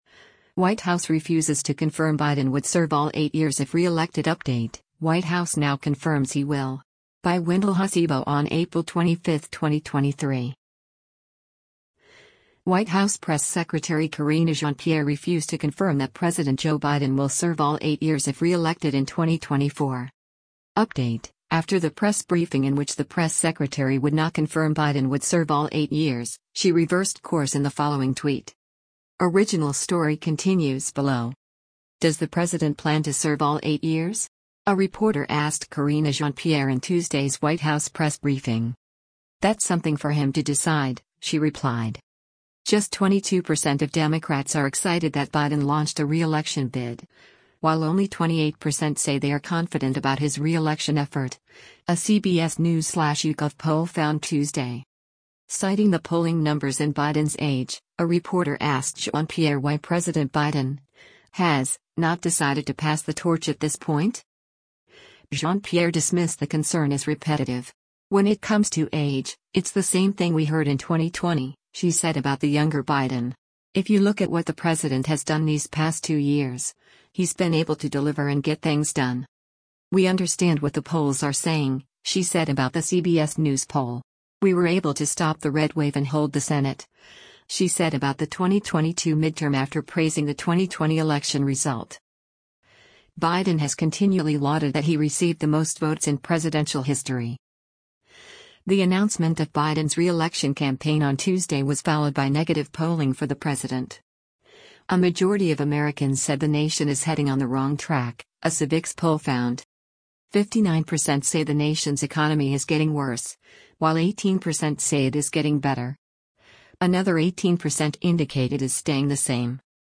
“Does the president plan to serve all eight years?” a reporter asked Karine Jean-Pierre in Tuesday’s White House press briefing.